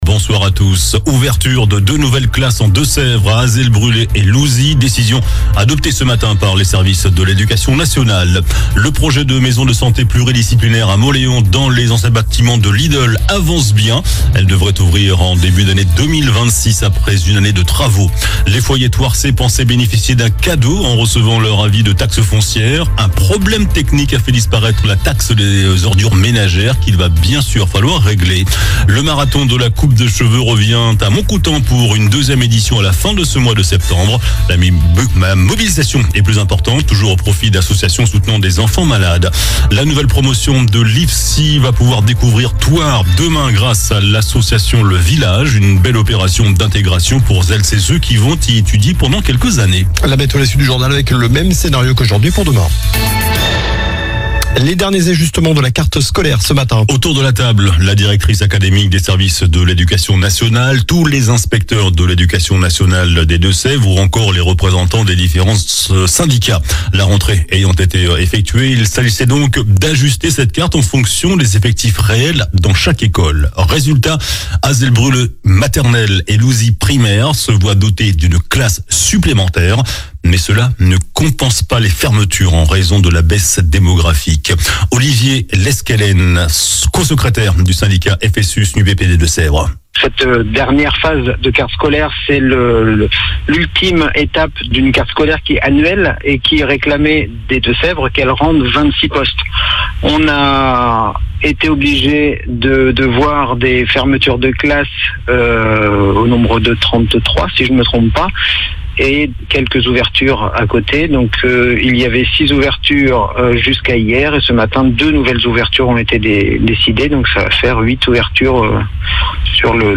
JOURNAL DU MERCREDI 04 SEPTEMBRE ( SOIR )